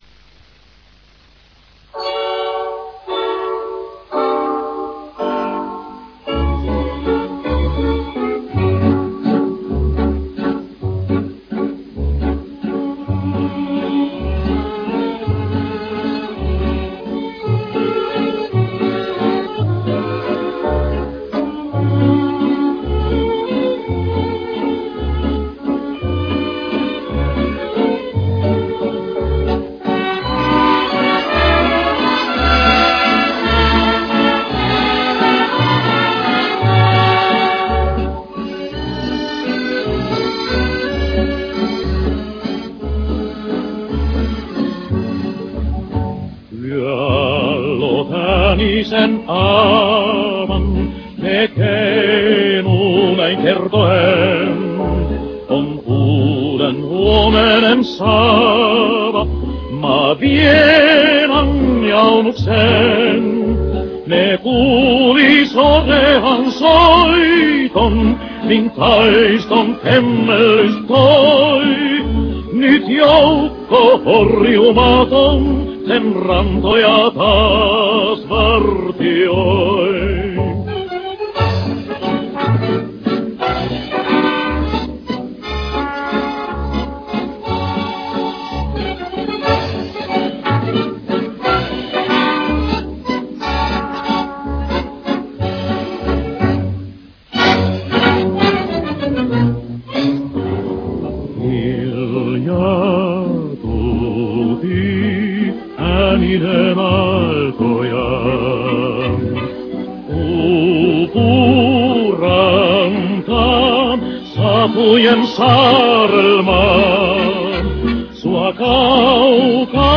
Wartime song 1942